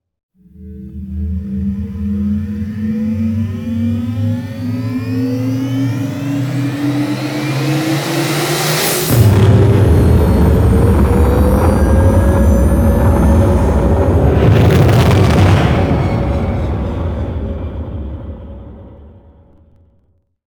OtherLaunch2.wav